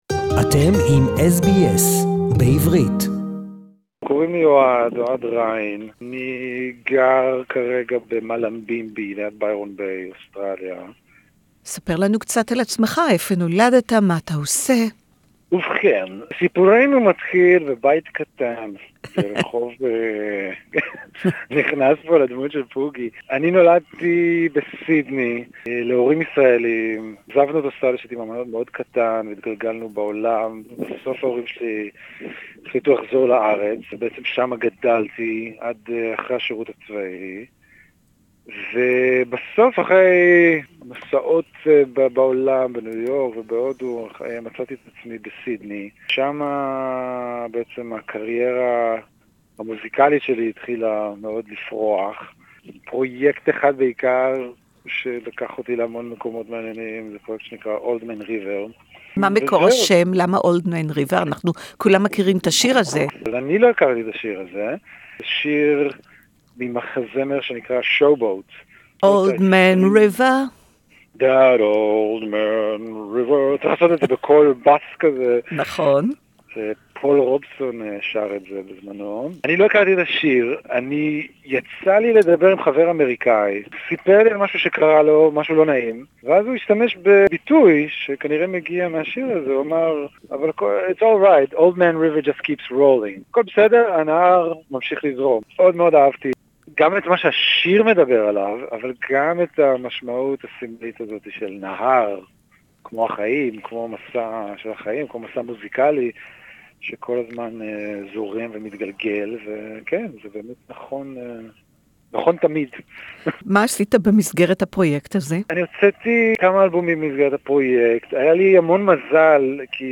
Hebrew Interview